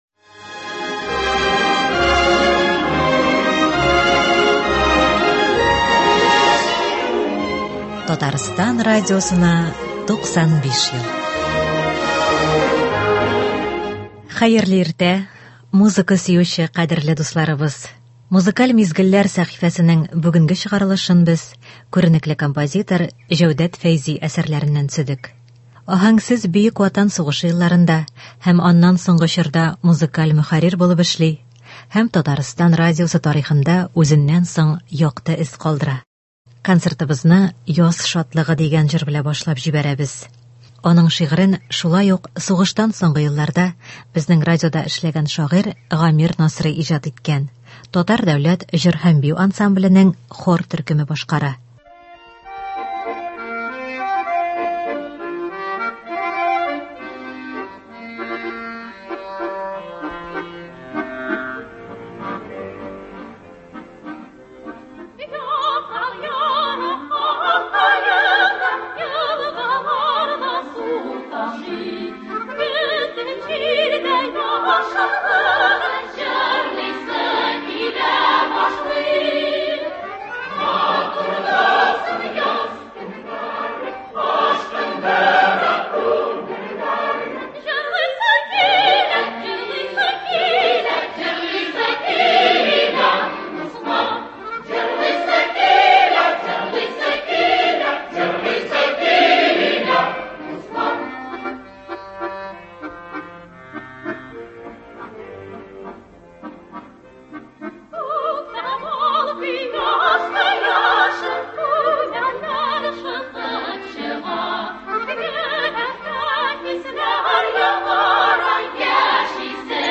Иртәнге концерт.